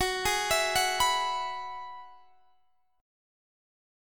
Listen to Gb7sus2sus4 strummed